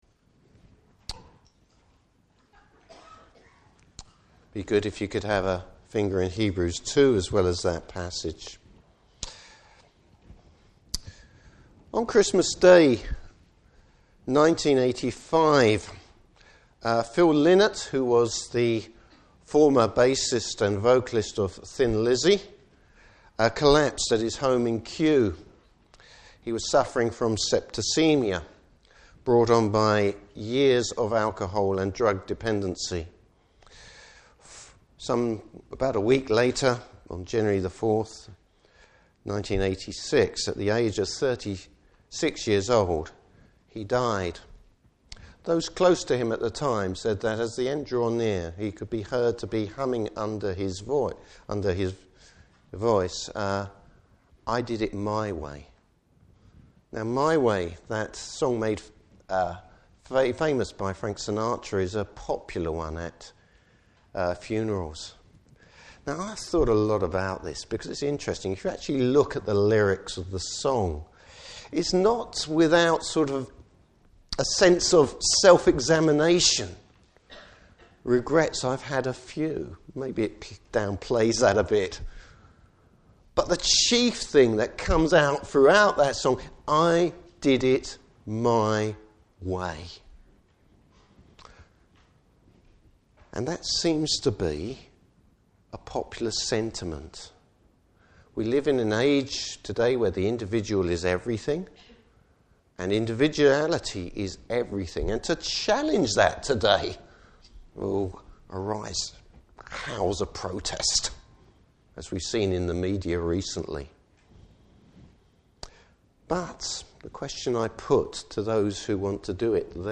Service Type: Good Friday Service.